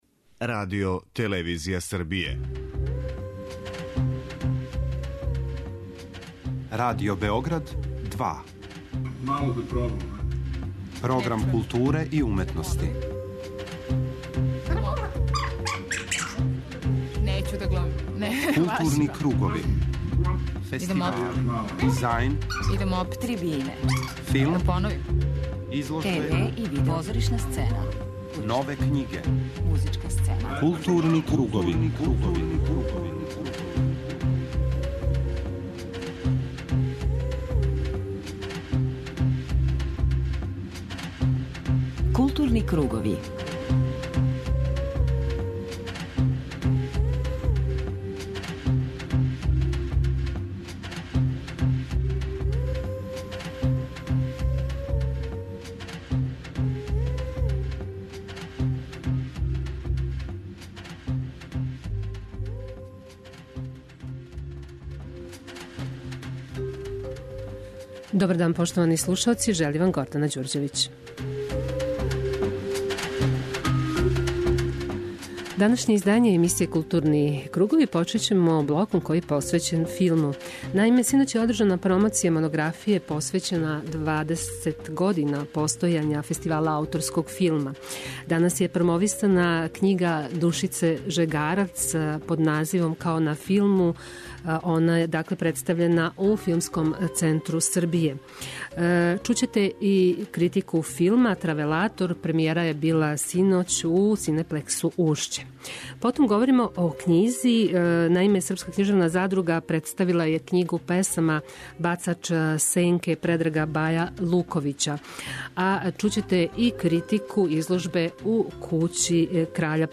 У знак сећања на недавно преминулог Јована Ћирилова, у данашњим Маскама чућете разговор снимљен са њим када је био гост у нашем студију поводом јубиларног 40. Битефа.